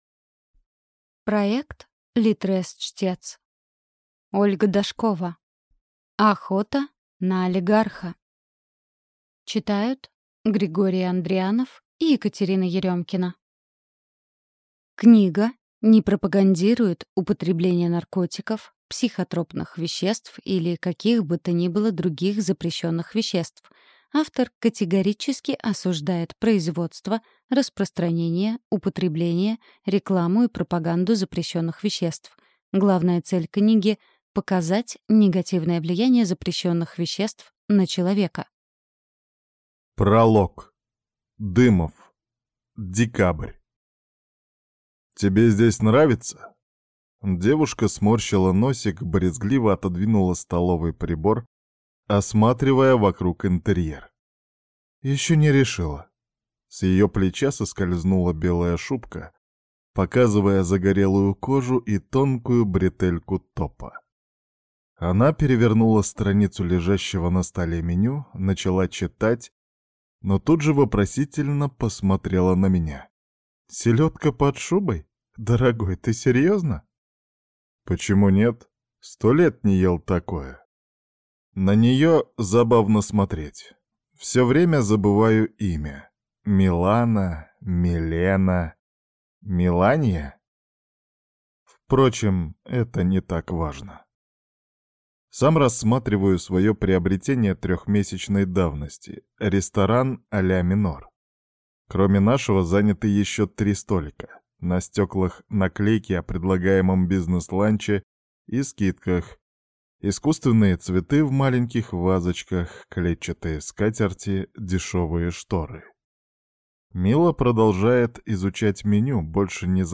Аудиокнига Охота на олигарха | Библиотека аудиокниг